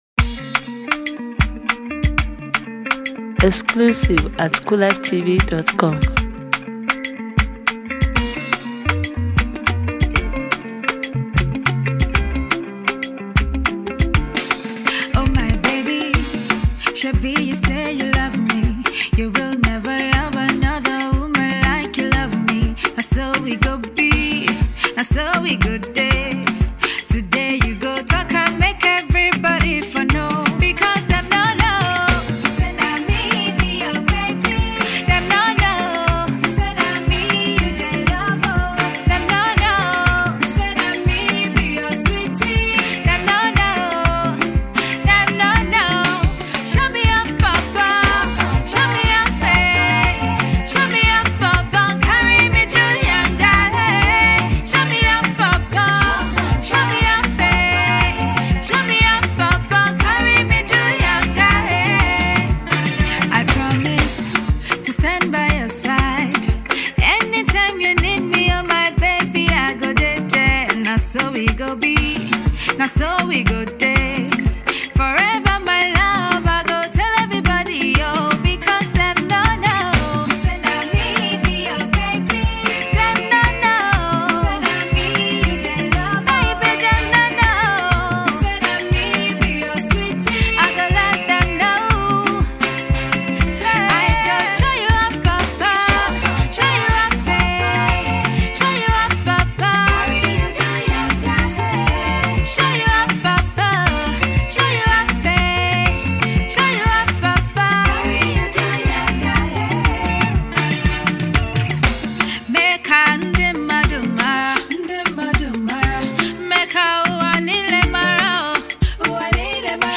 sings effortlessly